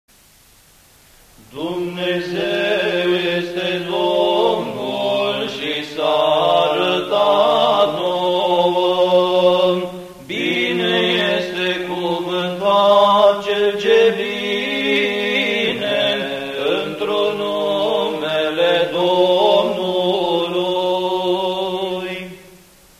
Cantari bisericesti Prima pagina >> Evenimente Acatiste Alte cantari Acatistul Sf Apostoli Petru si Pavel Acatist de multumire